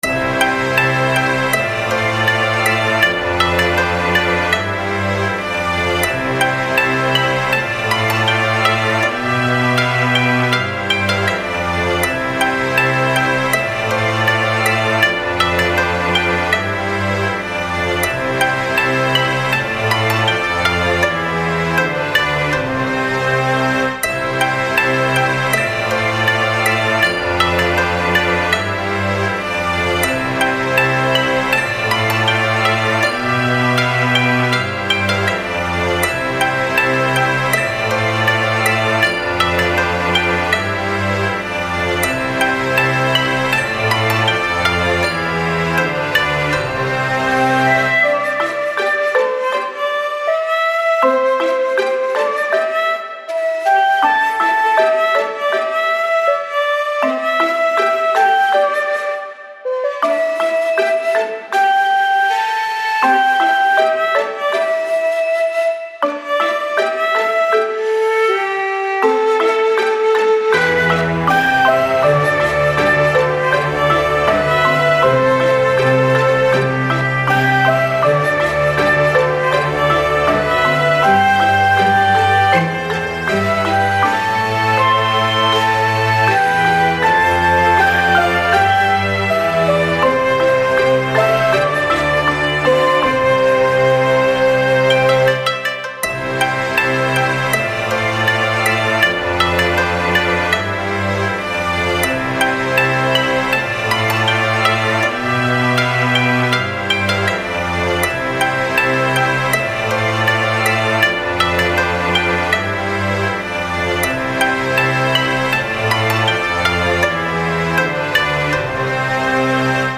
ストリングス尺八などの和楽器とオーケストラサウンドが重なり合う、幻想的で心癒される和風BGMです。
そよ風が草原を渡るように静かに始まり、徐々に広がっていく音の風景は、日本の自然や四季の美しさを感じさせます。
• ジャンル：和風BGM / 癒し / 自然 / 和楽器 × オーケストラ
• 雰囲気：幻想的 / 落ち着き / 優しさ / 静けさ / 日本的 / 風景音楽